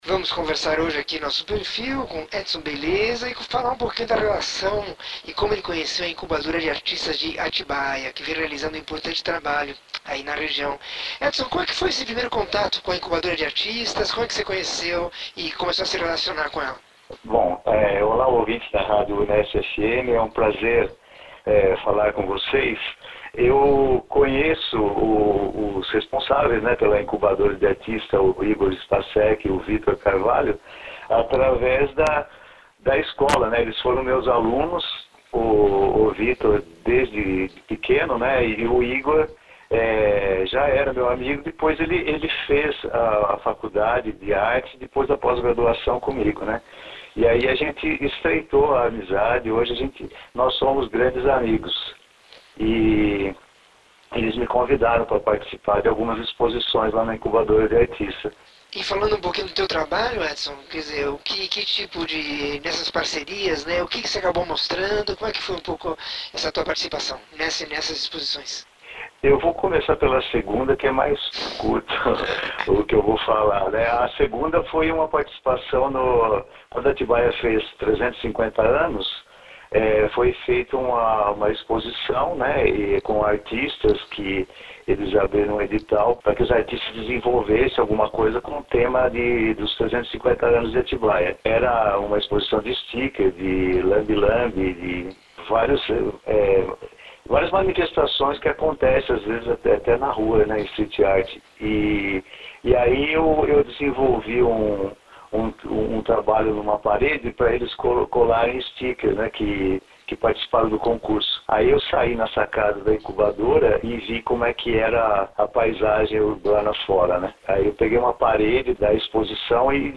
Entrevista com o desenhista, pintor, escultor e professor sobre seu trabalho e sua participação na Incubadora de Artistas de Atibaia.